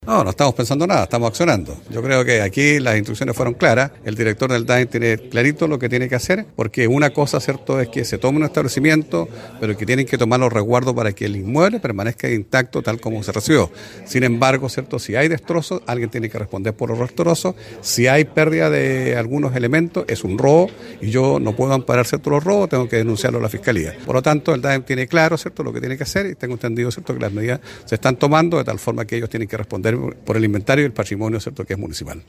El alcalde de Osorno, Jaime Bertin, en el seno de la sesión extraordinaria de concejo municipal desarrollada esta jornada, informó que el municipio recurrirá a la justicia por estos hechos.